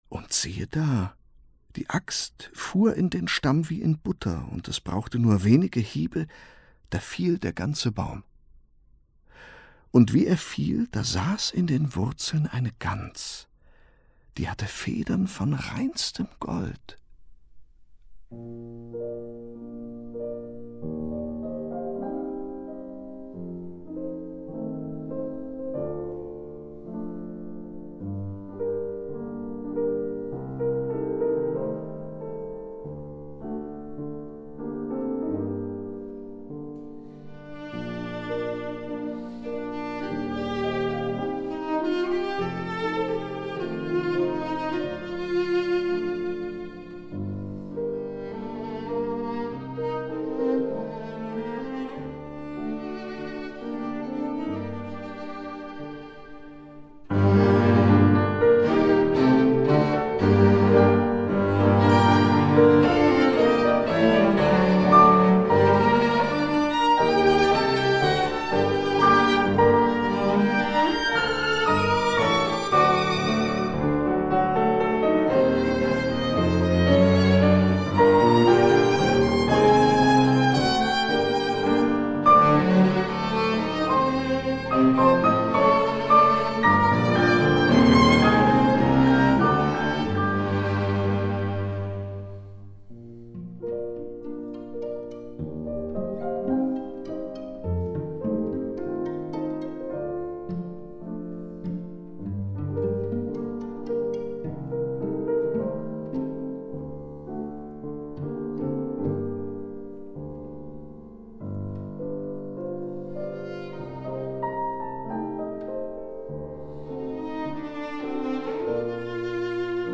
Violine
Violoncello
Klavier